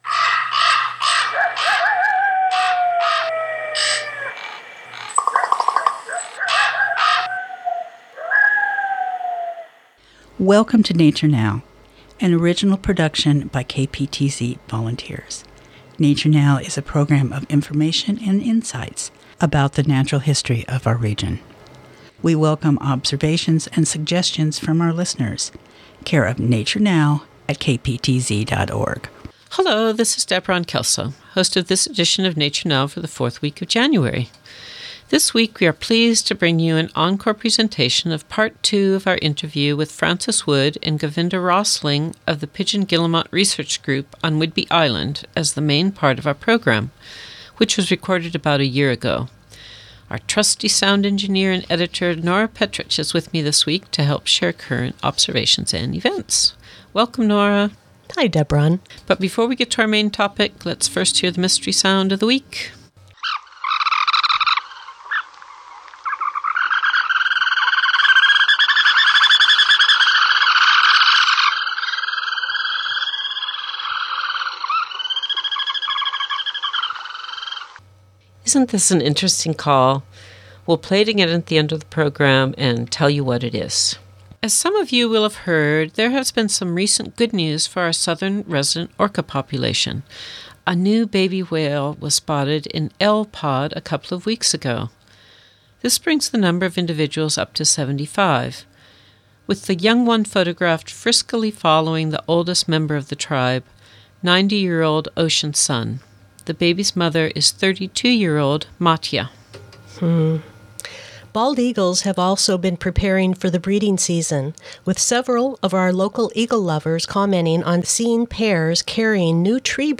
Nature-Now343-Interview2-Pigeon-Guillemot-Research-Group
Nature-Now343-Interview2-Pigeon-Guillemot-Research-Group.mp3